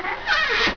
door_open_2.ogg